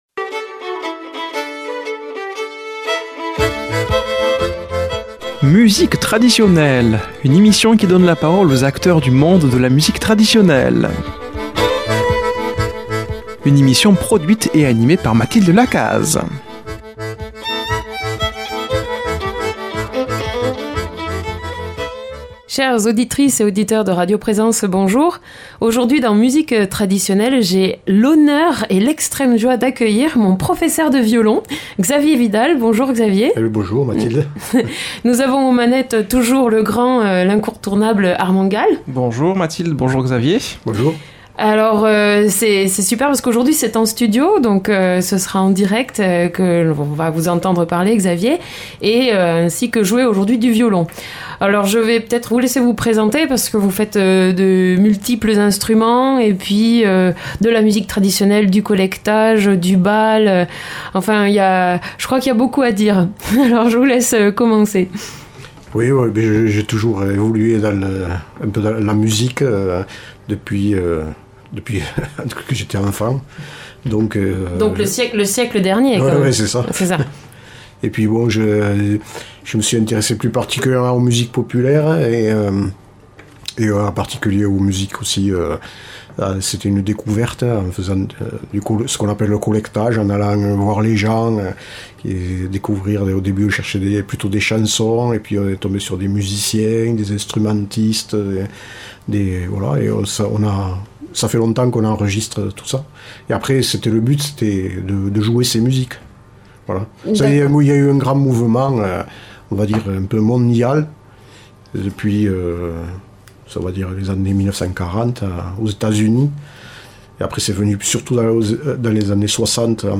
un entretien avec une personnalité de la musique traditionnelle